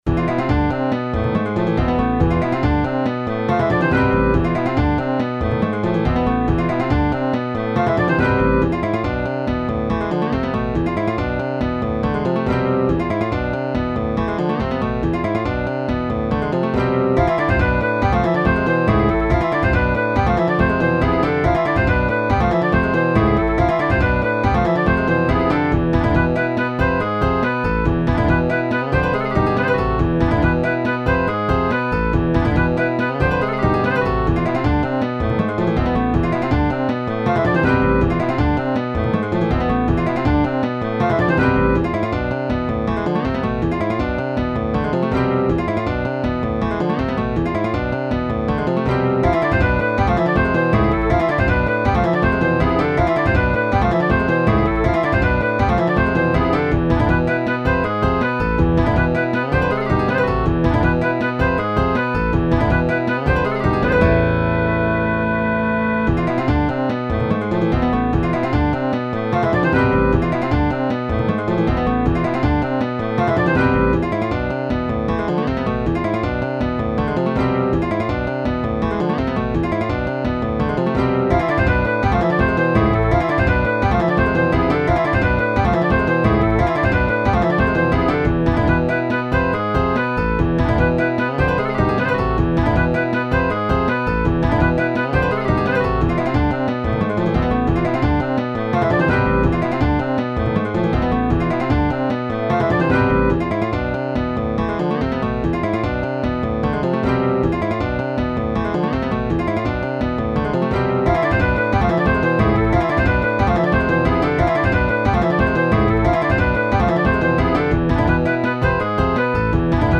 Ohmsägør, c'est une base metal ajoutant beaucoup d'éléments étrangers, du poprock au bebop en passant par le classique baroque, le death, le prog, la salsa.
La musique reste accessible mais particulièrement casse tête et technique, avec moults changements de tempo et harmonisations dangereusement...délicates, je dirais.
EDIT: J'ai rajouté une conversion midi en mp3, je sais que tout le monde n'a pas un midi génial donc ca donnera une idée un peu plus claire j'espère.